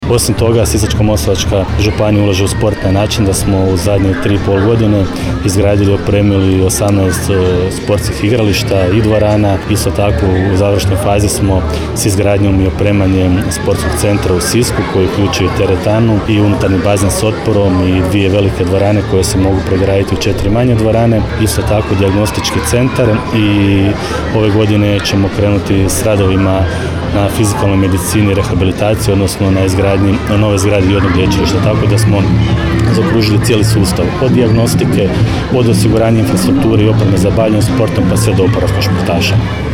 Župan Celjak dodaje